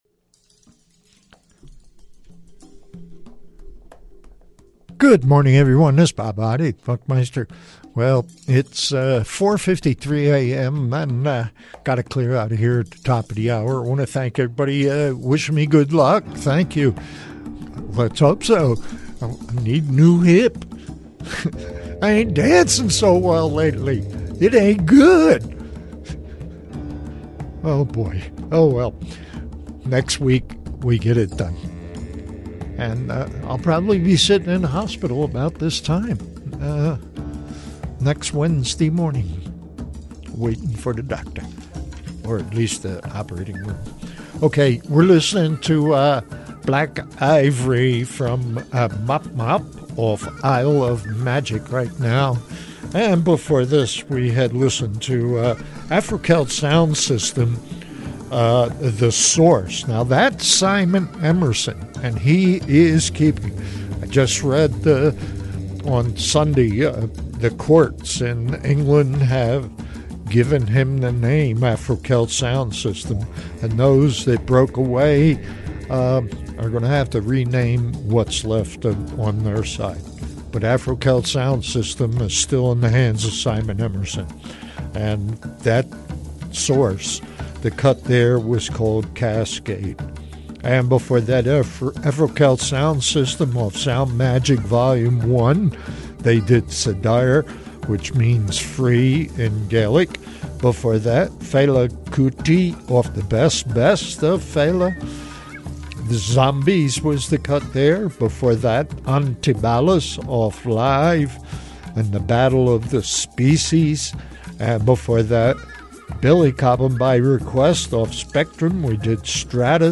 Fortunately, you can listen to a slice of an unforgettable voice and his famous sign-off that once so enriched New Mexico nights.